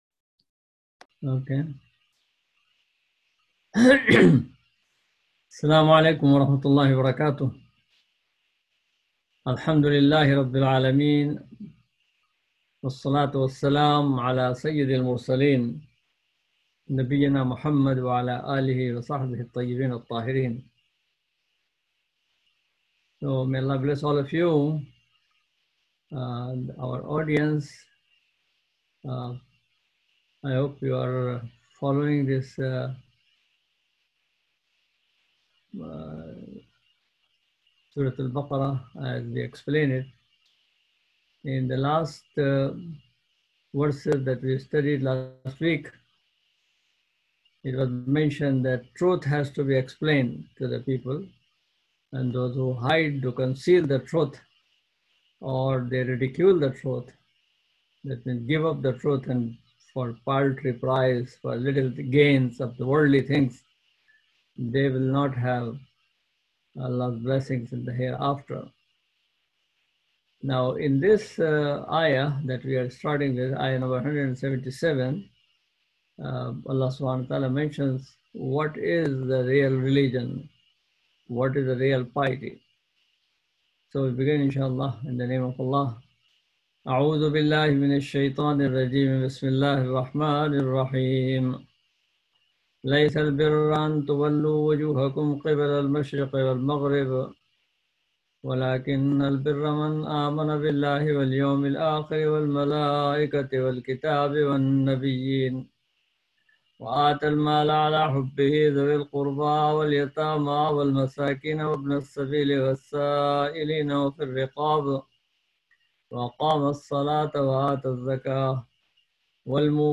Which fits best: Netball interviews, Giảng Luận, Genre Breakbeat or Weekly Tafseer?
Weekly Tafseer